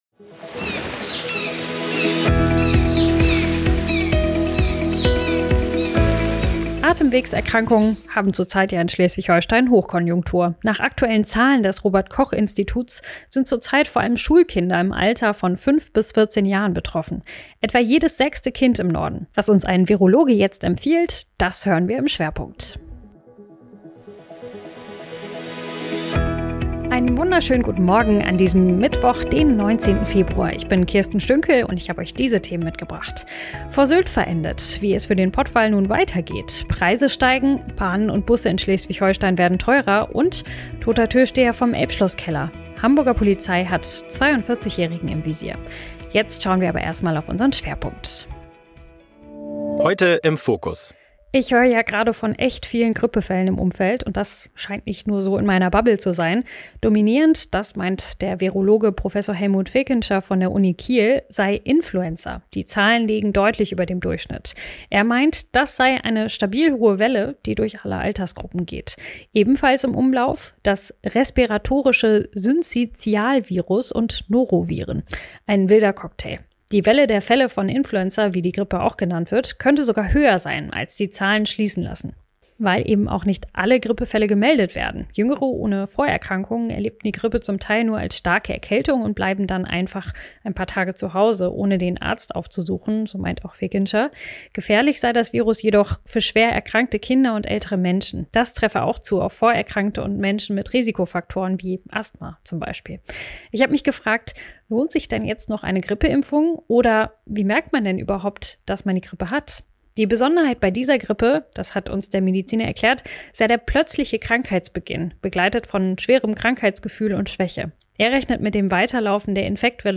Dein News-Podcast für Schleswig-Holstein
Was uns ein Virologe jetzt empfiehlt, das hören wir im